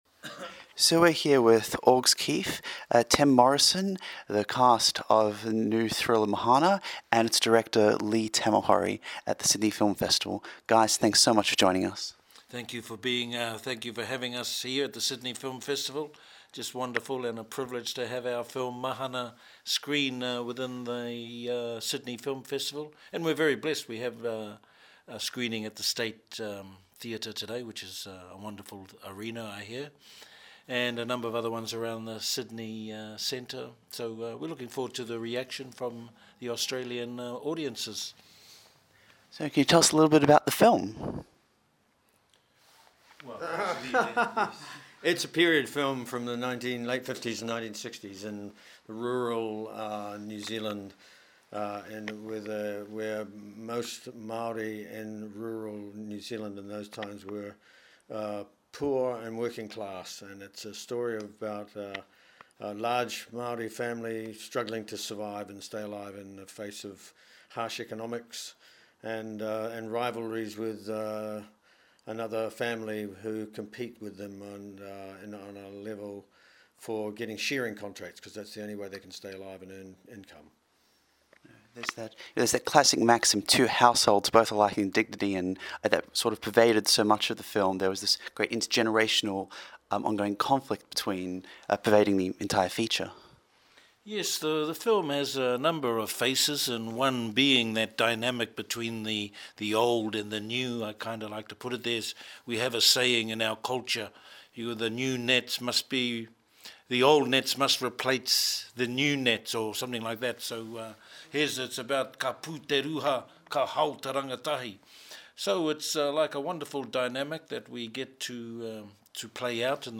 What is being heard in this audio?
Festivals, Interviews